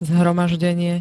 Zvukové nahrávky niektorých slov
7nir-zhromazdenie.spx